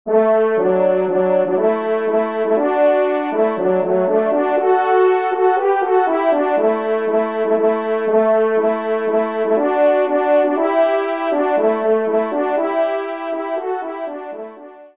Pupitre 2°Trompe